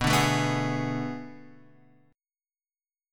B Minor 13th